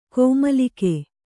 ♪ kōmalike